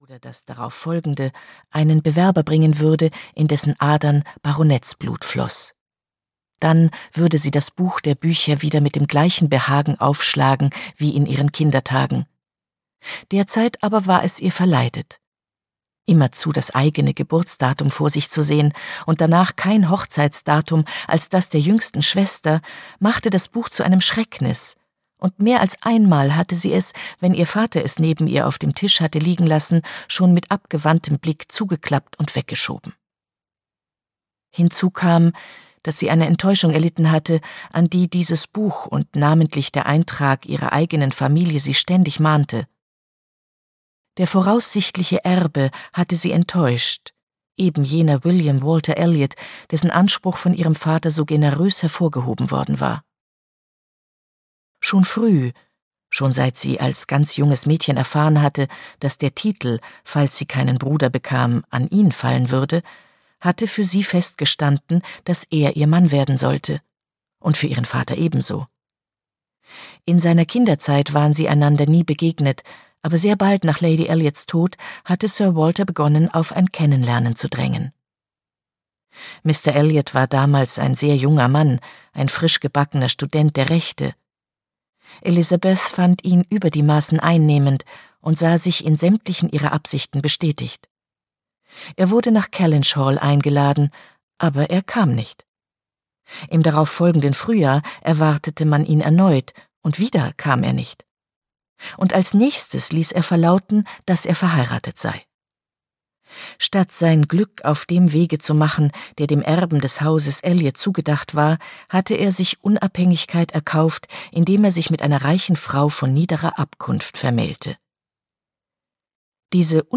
Anne Elliot - Jane Austen - Hörbuch